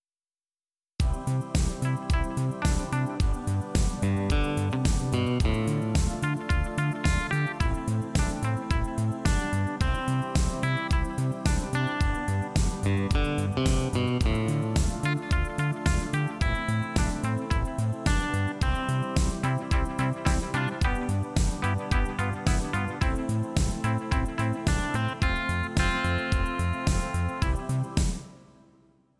Помогите, пожалуйста, найти песню в стиле Italo Disco 80-s